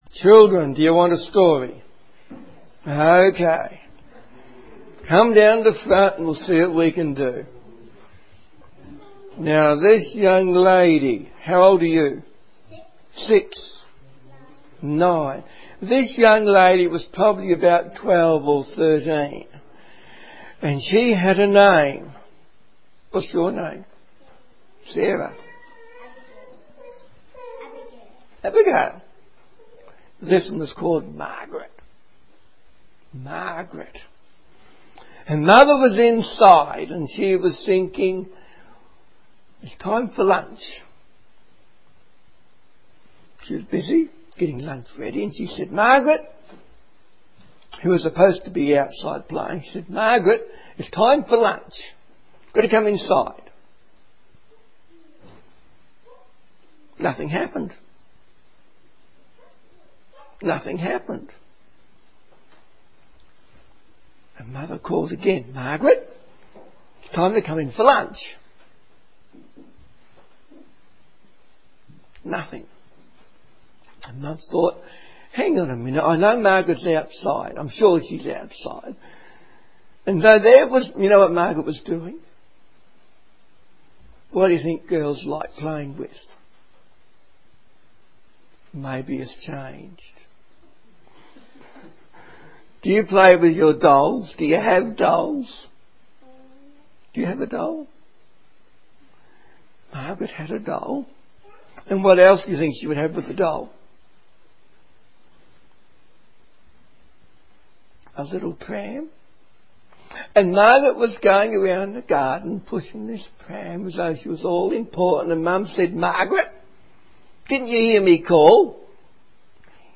Children's Stories